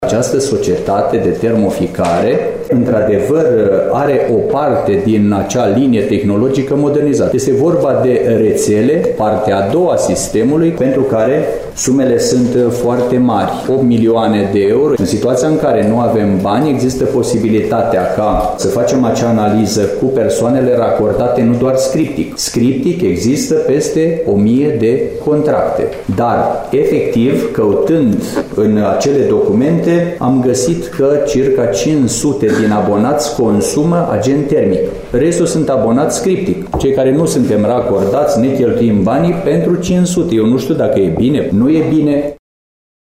Primarul muncipiului Reşiţa, Mihai Stepanescu: